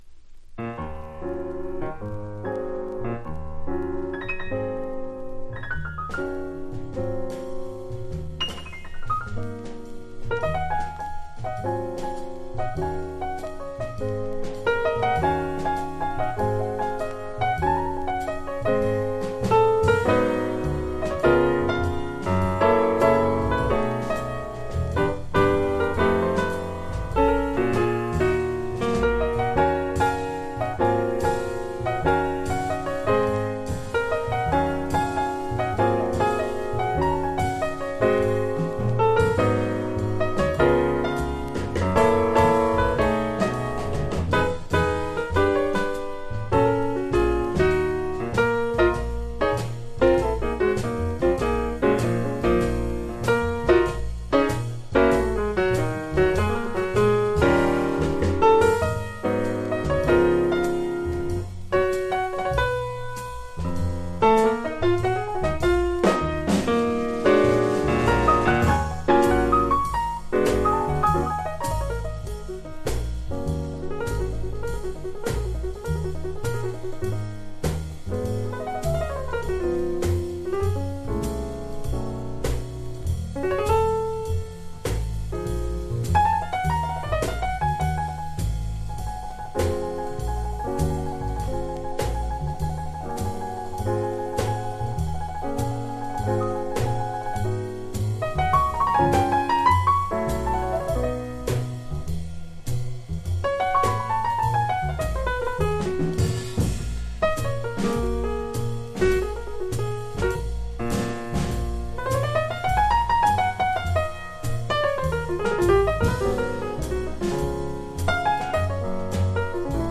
トリオもの好内容盤
（プレス・小傷によりチリ、プチ音、サーノイズある曲あり）※曲名をクリックすると…